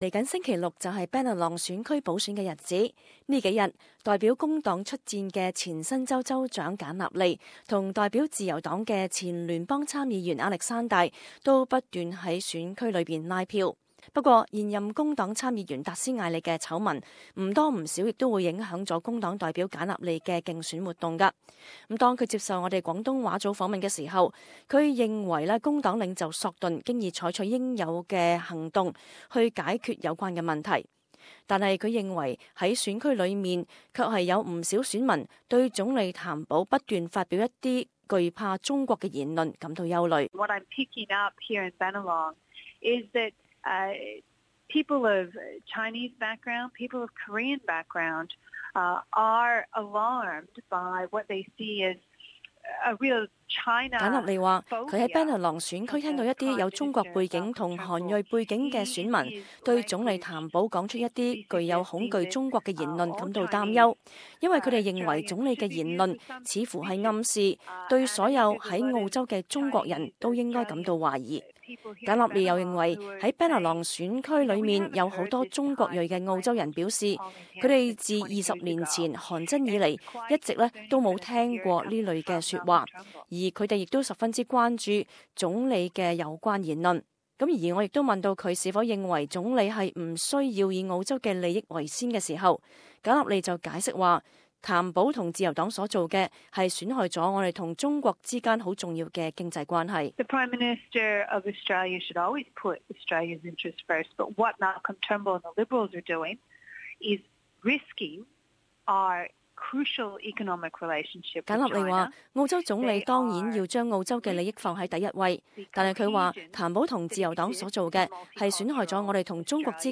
【Bennelong补选特辑】工党候选人简纳莉专访